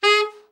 TENOR SN  25.wav